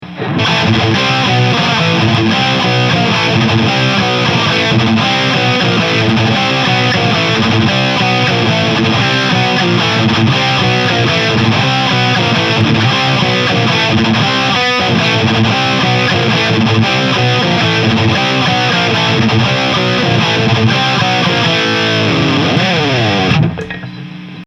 ロングサスティーン！
MXR DYNACOMPのサンプルサウンドです。
EMG89とJCM２０００とZW-44です。
歪ませてみると